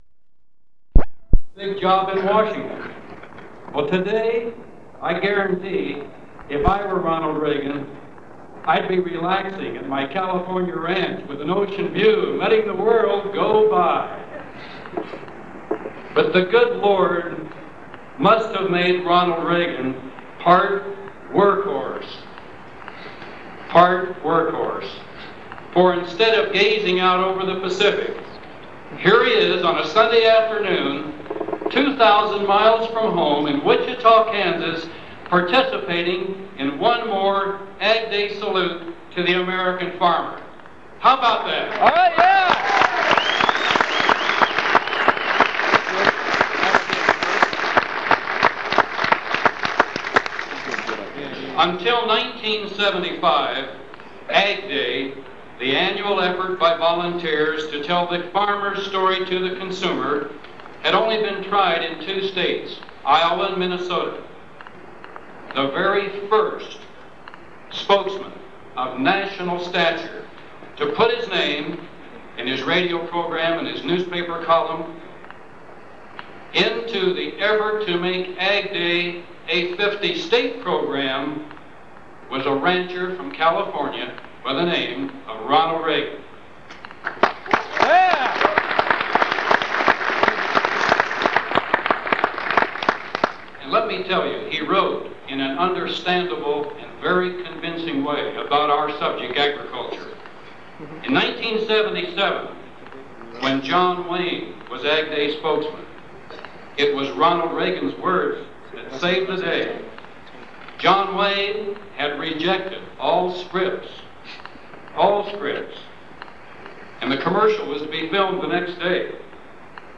MP3 Audio File Tape Number CD-5 COMP13 Date 03/23/1980 Location Wichita, Kansas Tape Length 40:57 Other Speakers Ronald Reagan